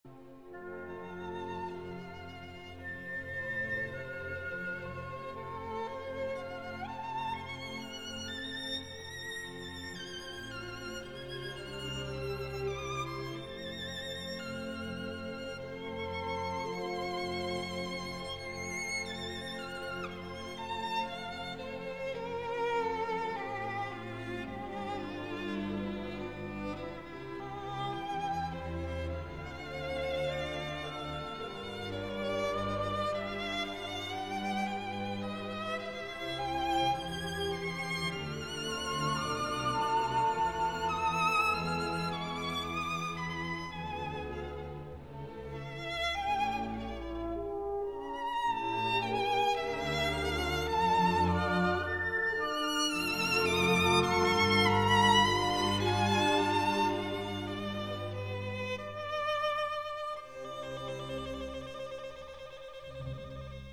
第二乐章慢板Adagio F大调A B A coda
乐团如歌谣般的表现，温暖高贵的旋律线条进行，如沐浴古典风格之中，小提琴独奏的自由飞翔，浮动音符流洩不安情绪，既梦幻又浪漫，宛如贝多芬小提琴协奏曲第一乐章的第二主题，也是追求古典和浪漫的美感。
主题A独奏小提琴2:26